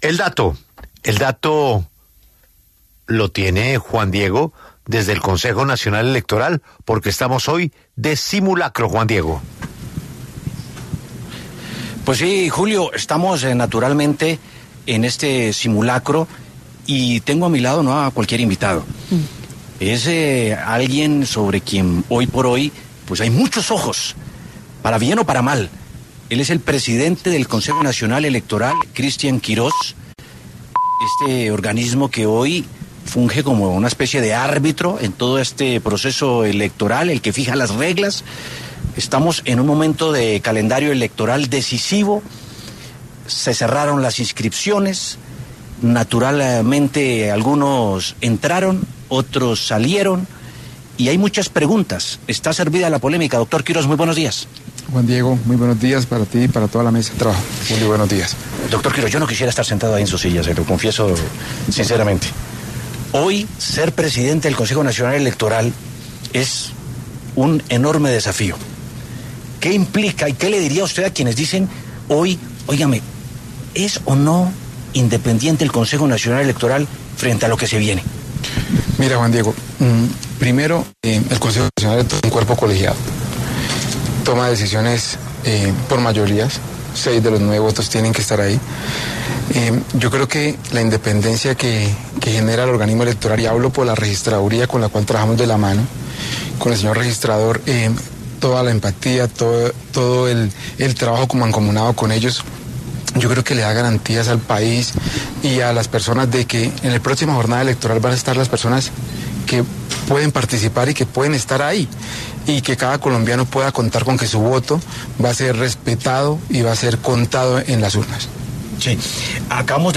El presidente del Consejo Nacional Electoral (CNE), Cristian Quiroz, estuvo en los micrófonos de 6AM W, con Julio Sánchez Cristo, para hablar de las elecciones 2026 y los retos que tienen para esos comicios.